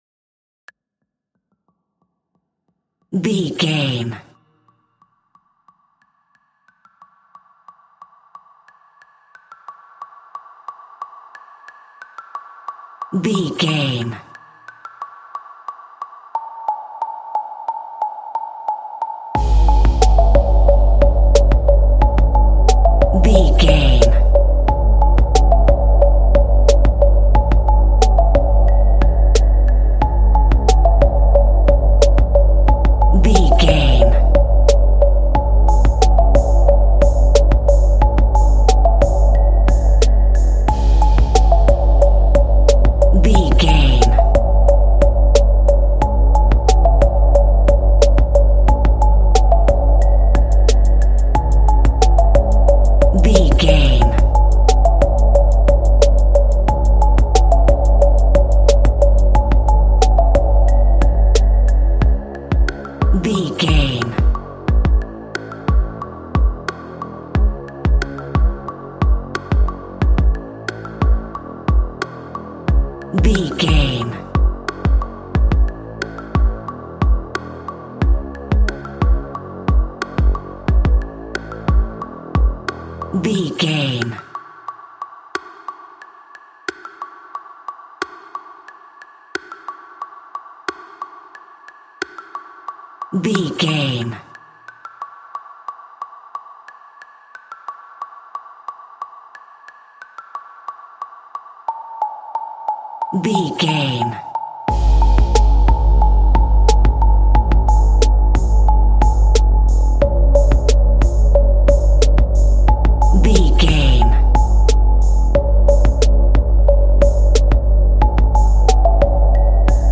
Aeolian/Minor
C#
hip hop
instrumentals
chilled
laid back
groove
hip hop drums
hip hop synths
piano
hip hop pads